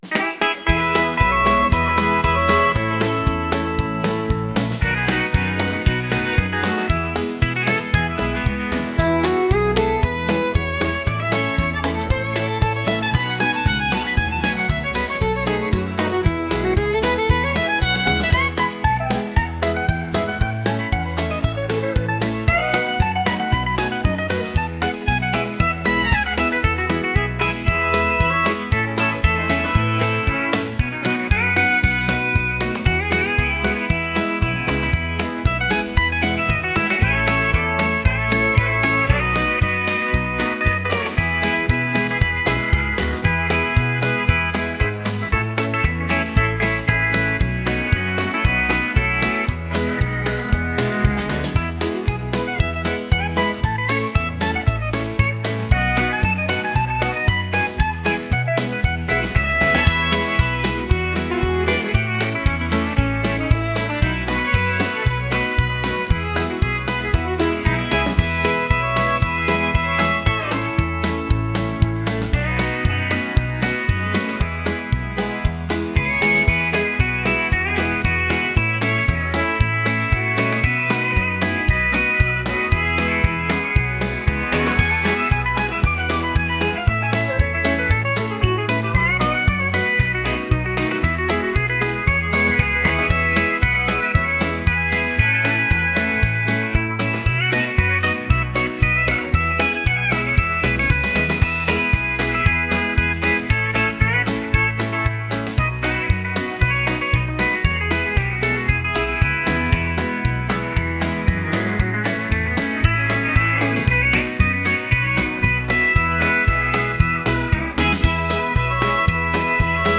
country | lively